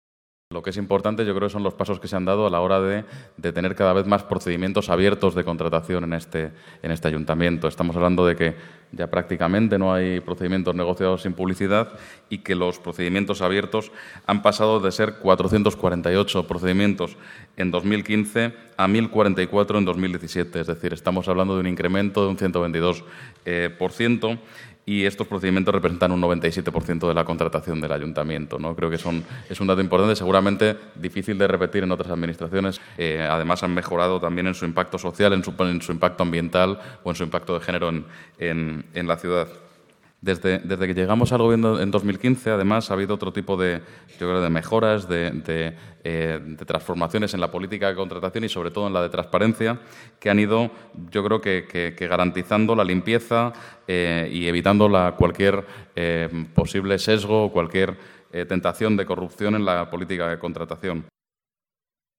Son datos que el delegado de Economía y Hacienda del Ayuntamiento, Jorge García Castaño, ha destacado hoy durante la presentación de la Guía de Contratación y PYMES, dirigida a pequeñas y medianas empresas, organizaciones empresariales y gestores municipales.
JGarciaCastañoJornadasContratacion-25-05.mp3